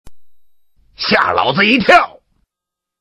SFX游戏吓老子一跳音效下载
SFX音效